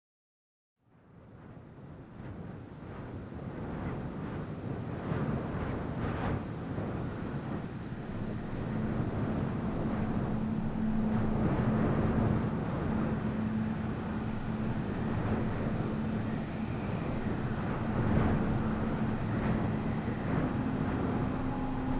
Звуки ветра в пустыне
Атмосферный шепот ветра в пустыне (для театральных постановок)